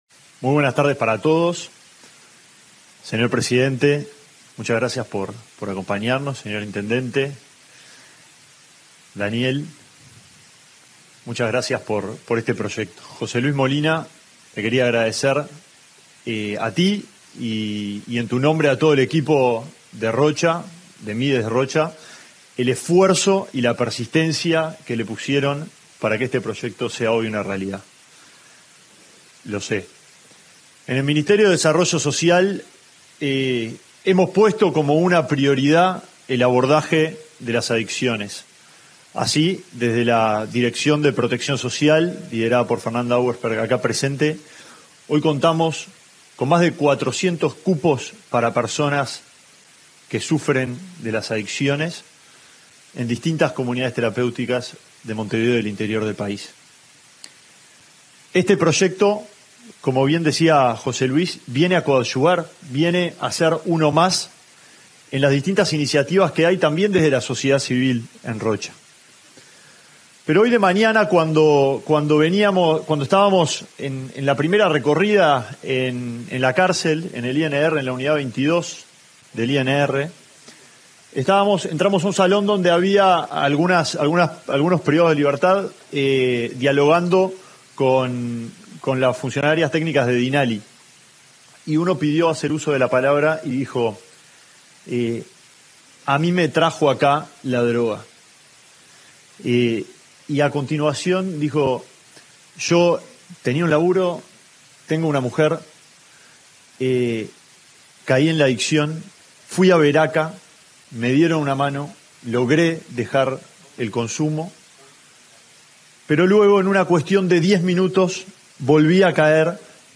Palabras del ministro de Desarrollo Social, Alejandro Sciarra
En el marco de la inauguración de un dispositivo Ciudadela de la Secretaría Nacional de Drogas (SND) para establecer un sistema de atención para